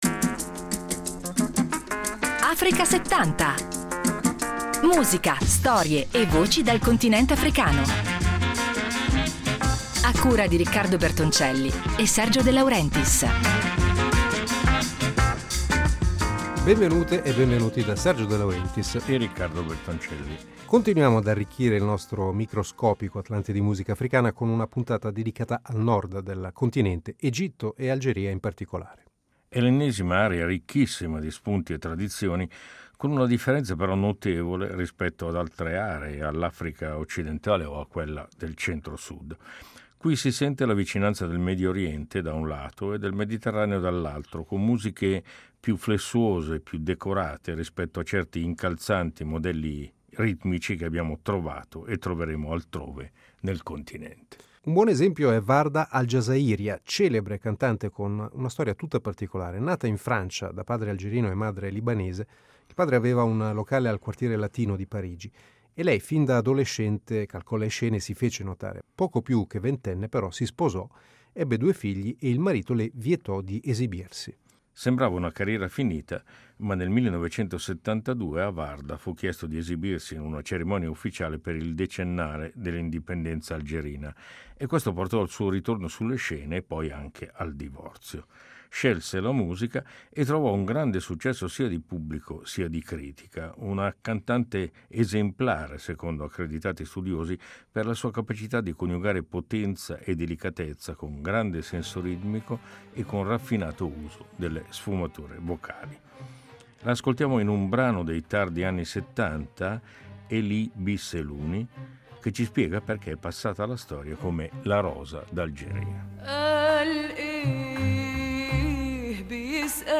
Occidente e Medioriente a ritmo mediterraneo: Egitto e Algeria